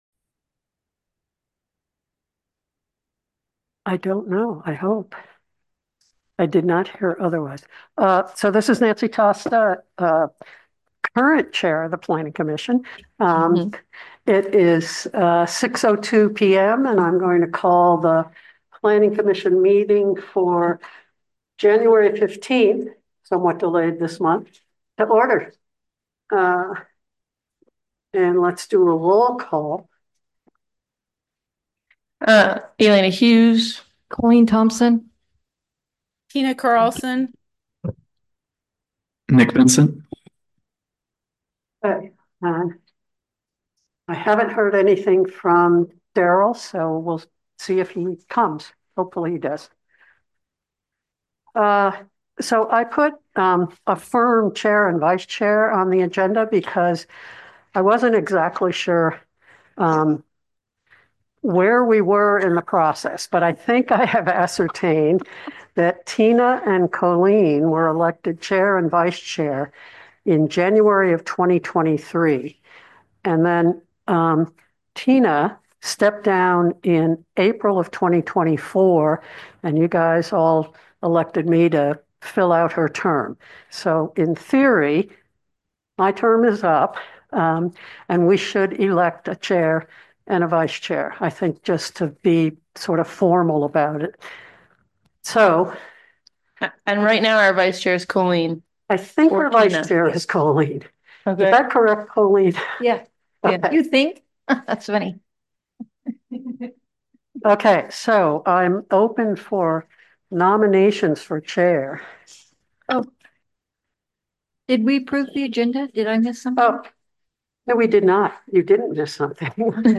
Planning Commission, Regular Meeting, Thursday, January 15, 2025, 6pm | Boulder, Utah
The Boulder Town Planning Commission will hold a regular meeting on Thursday, Jan 15, 2025, starting at 6:00 pm at the Boulder Community Center Meeting Room, 351 No 100 East, Boulder, UT. Zoom connection will also be available.